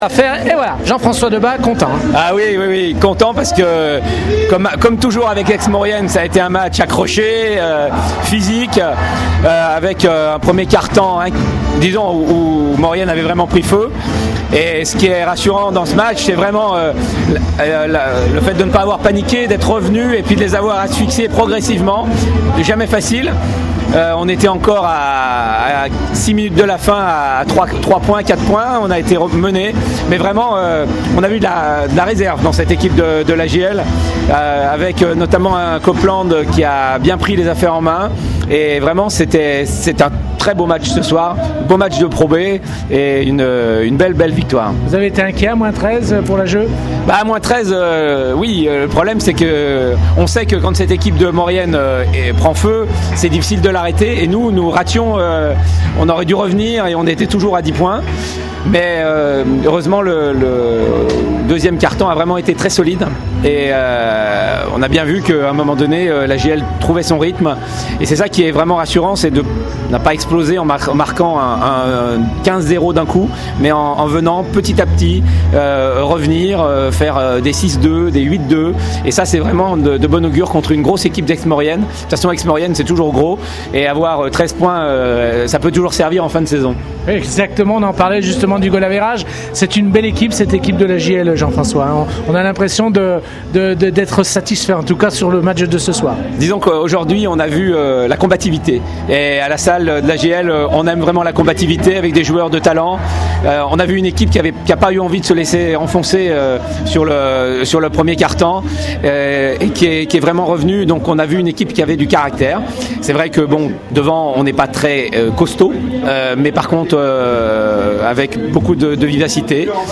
Les Bréssans ont fait preuve de patience et de combativité pour venir à bout des Savoyards, on écoute les diverses réactions d’après-match au micro